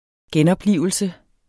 Udtale [ ˈgεnʌbˌliˀvəlsə ]